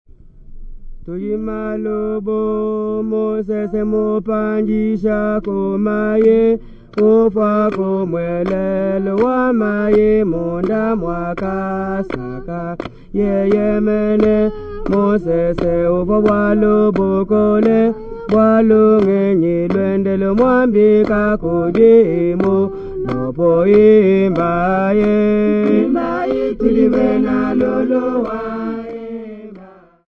Group of Lulua men and women
Folk music--Africa
Field recordings
Africa Zimbabwe Wankie Colliery f-rh
Indigenous folk song for the inauguration of a chief, with singing and clapping.